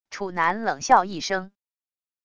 楚男冷笑一声wav音频